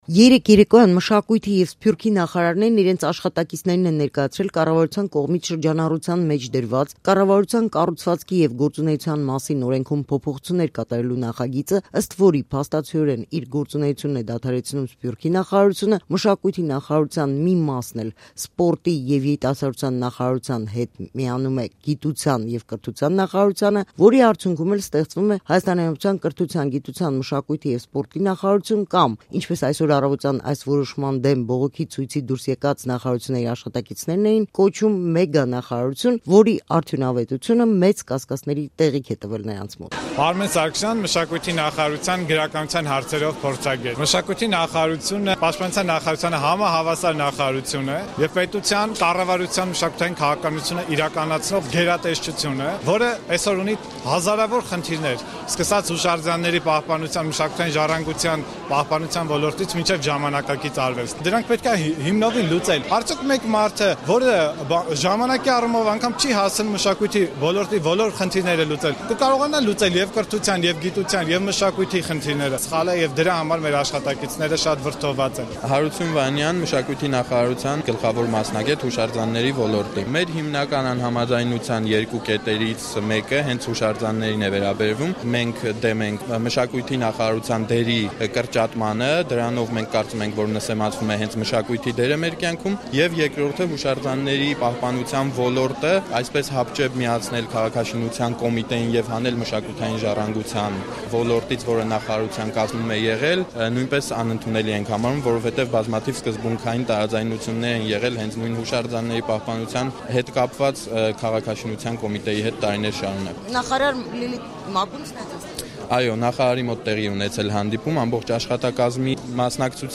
Բողոքի ցույց կառավարության շենքի մոտ Մշակույթի և Սփյուռքի նախարարությունները չլուծարելու պահանջով
Ռեպորտաժներ